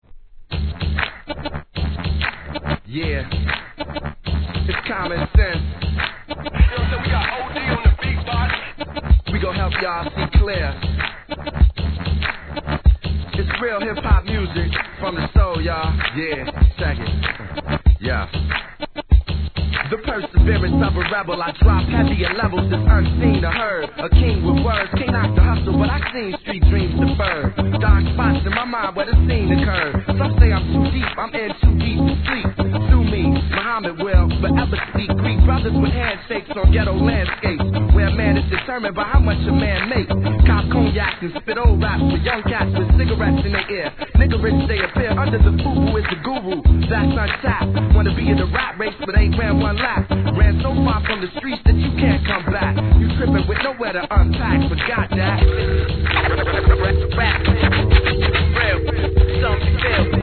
1. HIP HOP/R&B
人気曲を洒落オツ＆JAZZYにREMIXする人気シリーズ!!